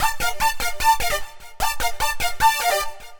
Index of /musicradar/future-rave-samples/150bpm